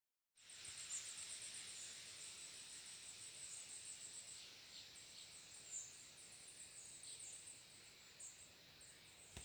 Birds -> Warblers ->
Goldcrest, Regulus regulus